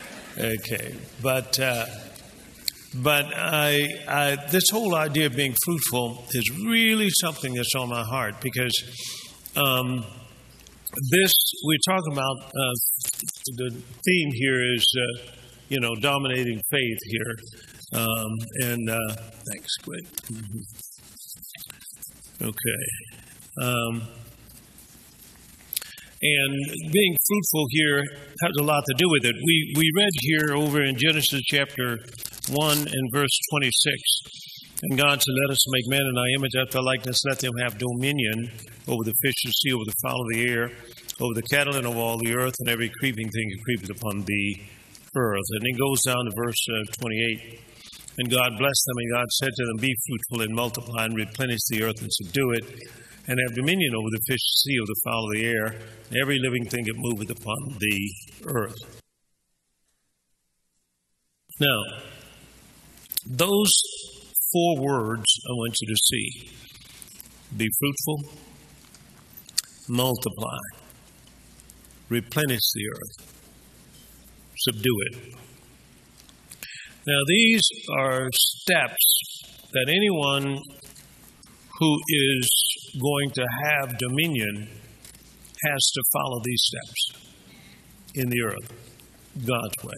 This powerful set includes both evening and daytime sessions, and gives you an opportunity to relive the excitement and delve even deeper into the revelation and anointing of the conference.